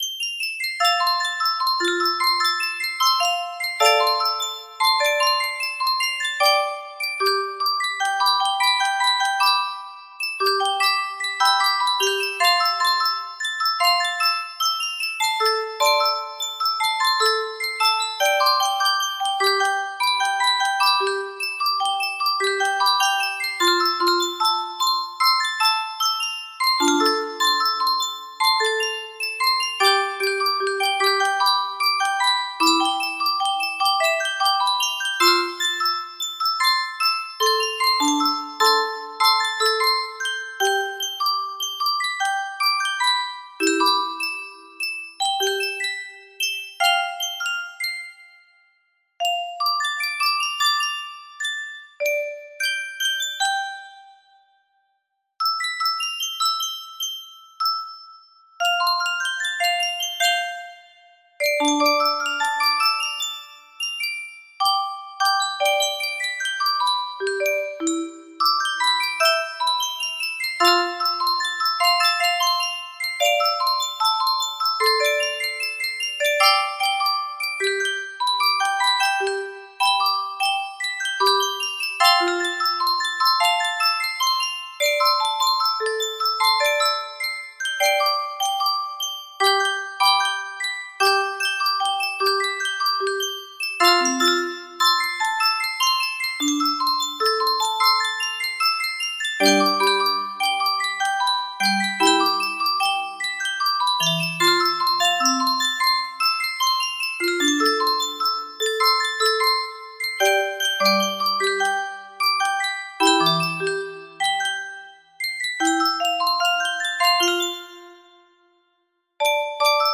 Full range 60
Done, Proper tempo, No Reds. Enjoy.